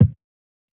KICK LOW END I.wav